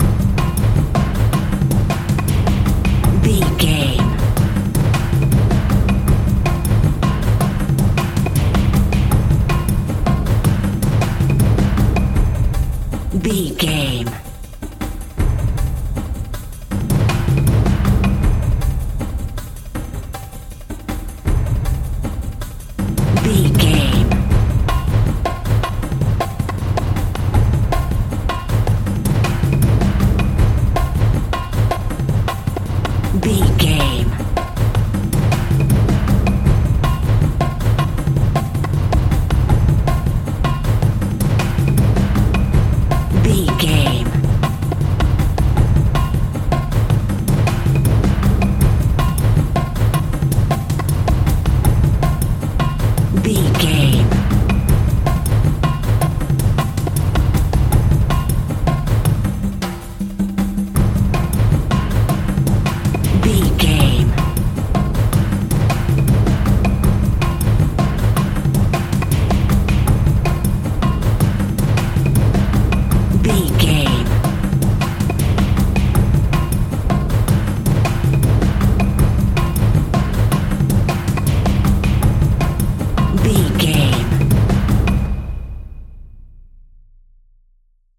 Atonal
Fast
scary
tension
ominous
dark
suspense
haunting
eerie
drums
percussion
synth
keyboards
ambience
pads
eletronic